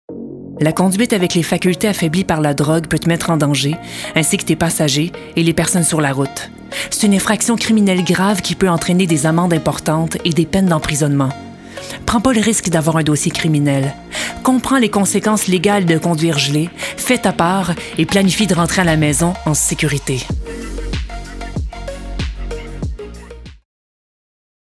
Timbre Grave - Médium
Prévention conduite - Bienveillante - Affirmée - Québécois naturel /
Pub sociétale + Annonceuse - Fictif 2023 0:24 987 Ko